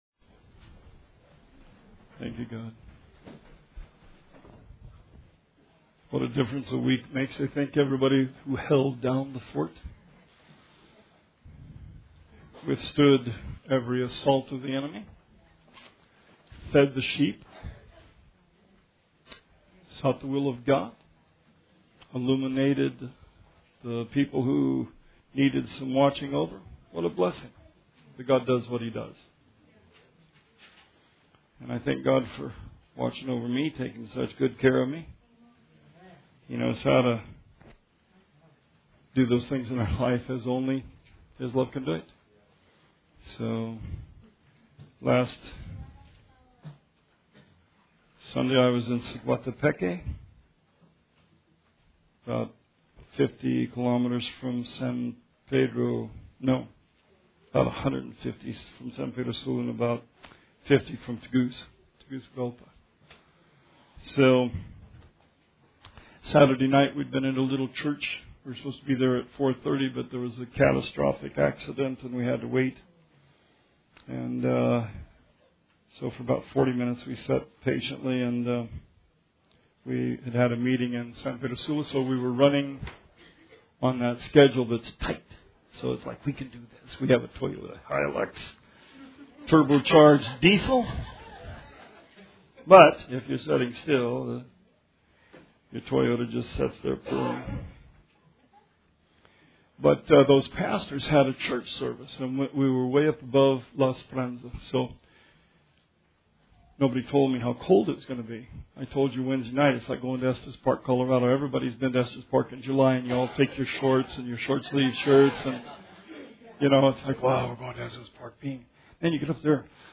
Sermon 9/2/18